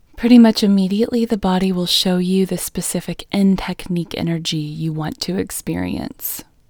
LOCATE IN English Female 27